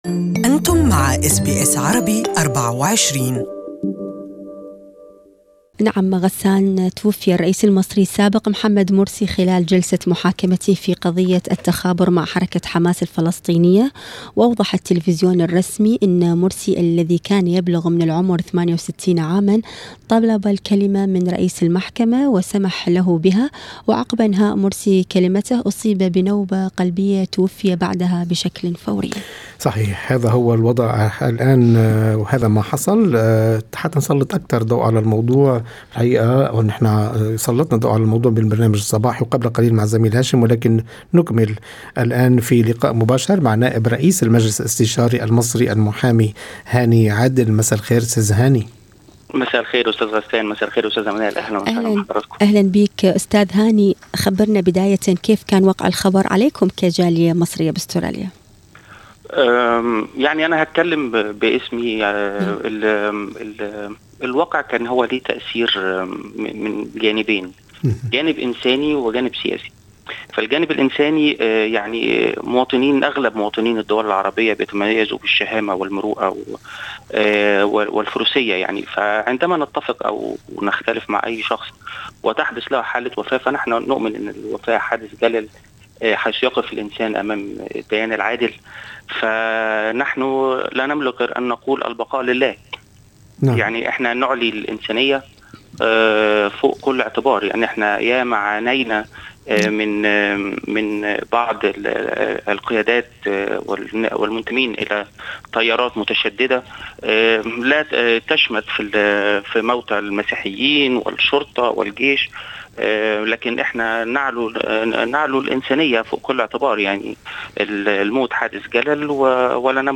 المزيد في لقاءِ مباشر